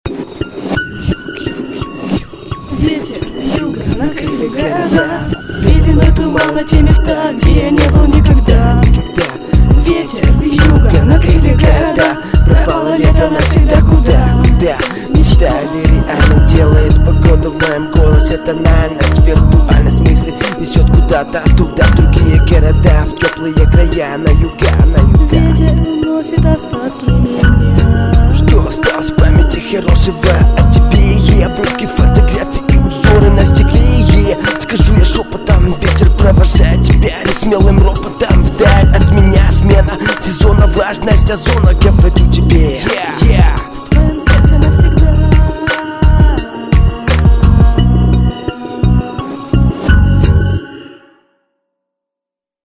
Мега-попса
тётя у тебя хорошая в трэке без базара
... а звук шкалит малость... и читки чёт незаметно... как будто она там не в тему ) ... шютка... для демки весьма неплохо... но зашкалити убрать надо будет стопудова... респекта кароче..